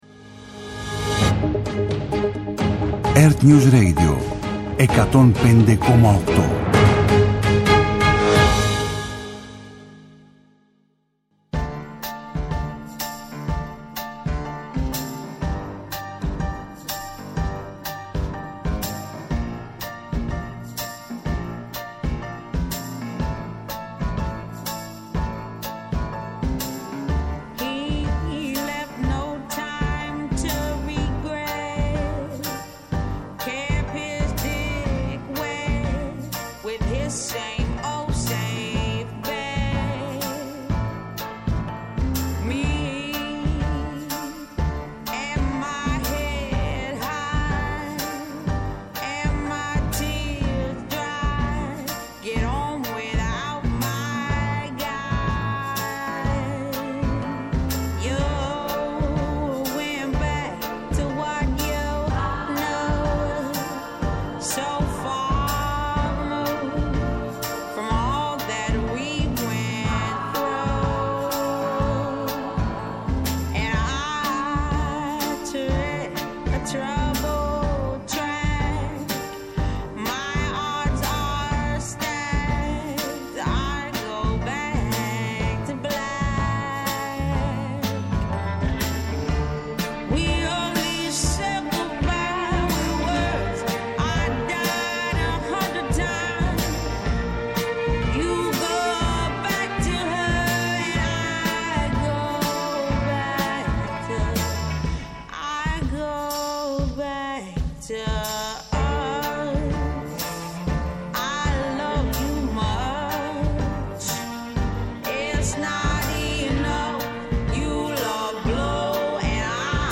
-Και ο Κωνσταντίνος Αρβανιτόπουλος, καθηγητής Διεθνούς Πολιτικής στο Πάντειο Πανεπιστήμιο, για τον Τραμπ και τον πόλεμο στη Μέση Ανατολή.